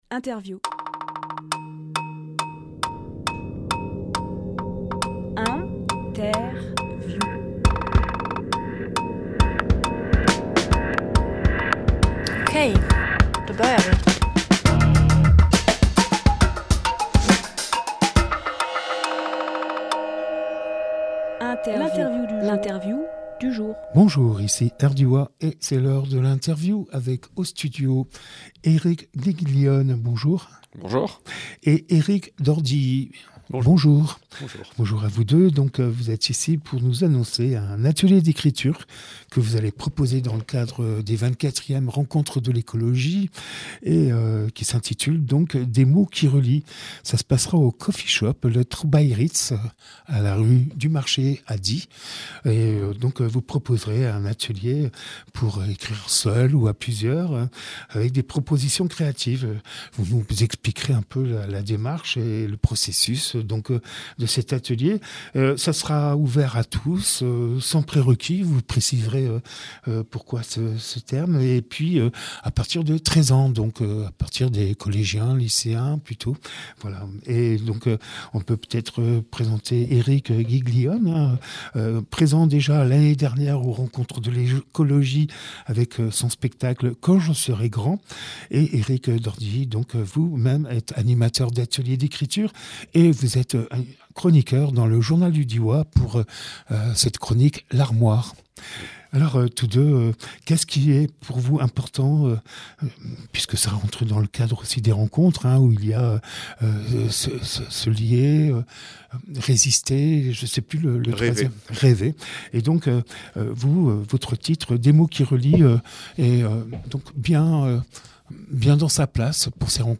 Emission - Interview Des mots qui relient au café Trobaïritz Publié le 24 janvier 2026 Partager sur…
lieu : Studio RDWA